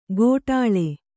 ♪ gōṭāḷe